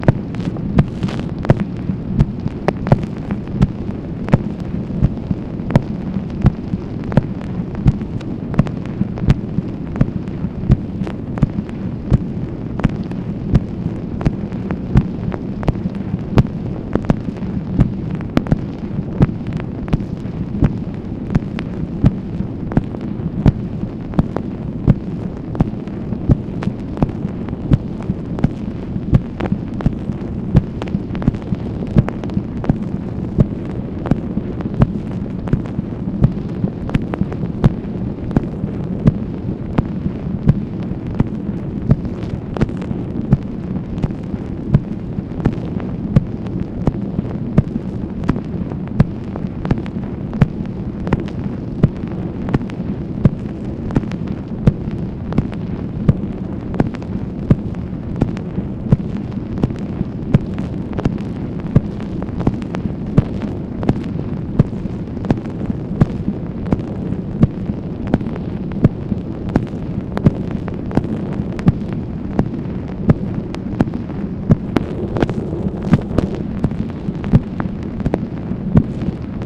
MACHINE NOISE, July 21, 1965
Secret White House Tapes | Lyndon B. Johnson Presidency